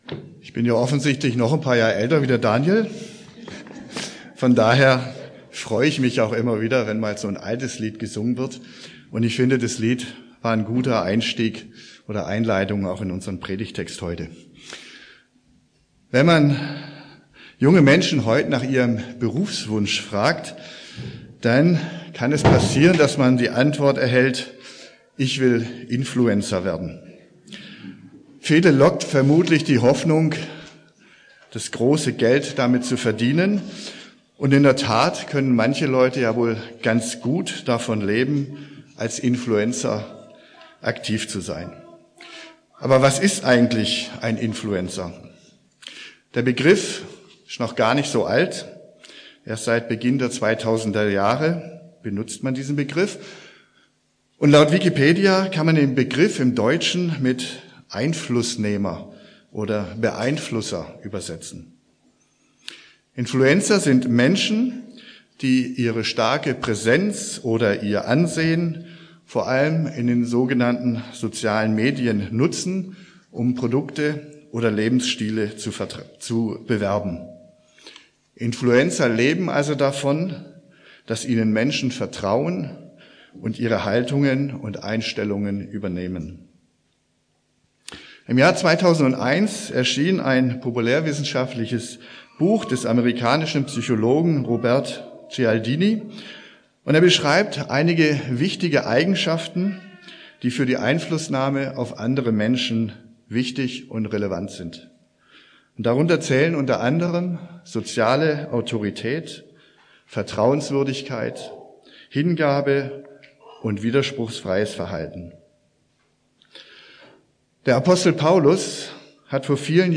Adel verpflichtet - Phil. 3,17-4,5 ~ Predigten aus der Fuggi Podcast